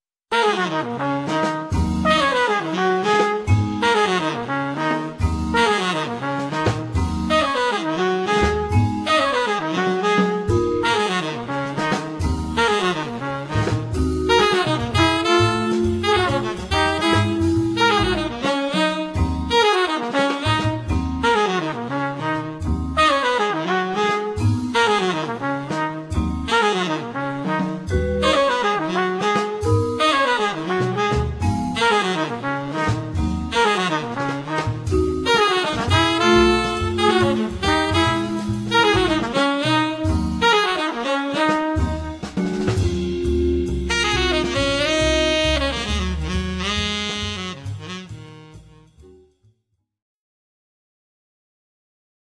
The brand of jazz played is sensible and melodic post-bop.
tenor saxophone
trumpet
vibes
bass
drums
long, carefully constructed solo lines